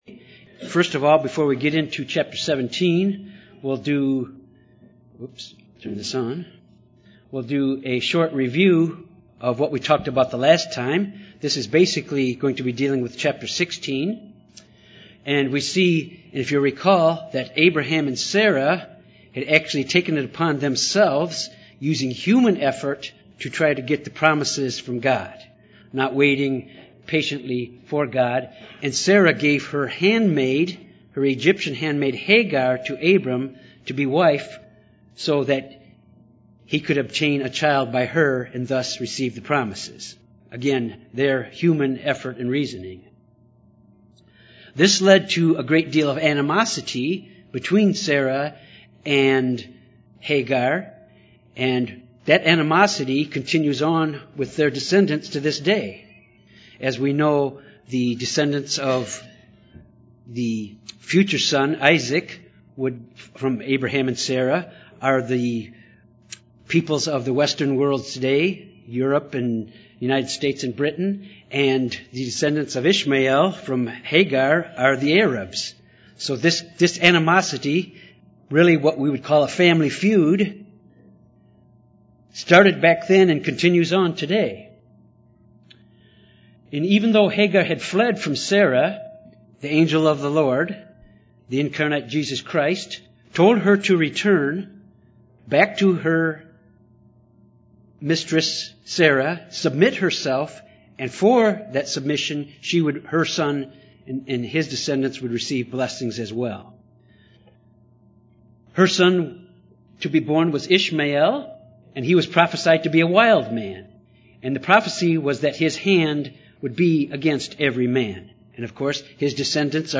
This Bible study focuses on Genesis 17-19. God's covenant with Abraham, through Isaac.